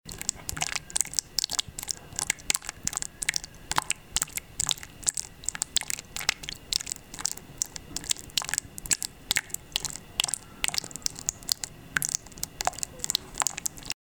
Contínuo goteo de un grifo
Grabación sonora del sonido rápido y contínuo del goteo de un grifo.
Sonidos: Agua